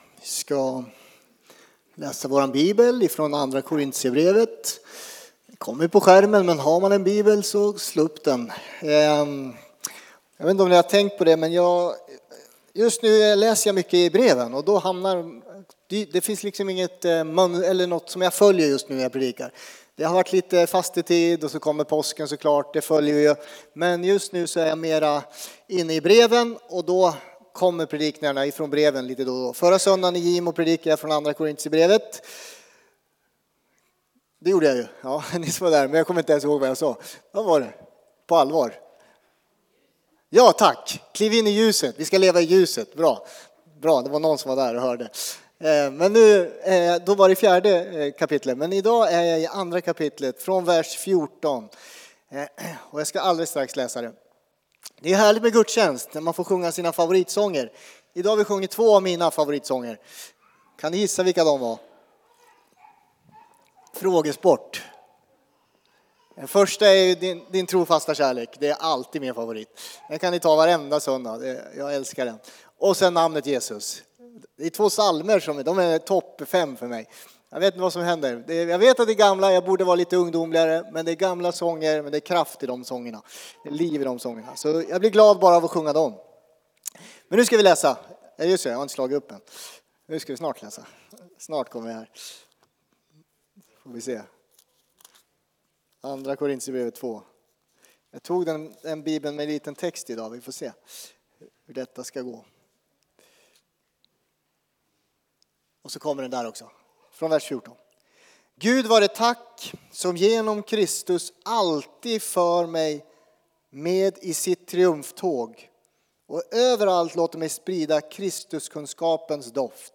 Furuhöjdskyrkan, Alunda Gudstjänst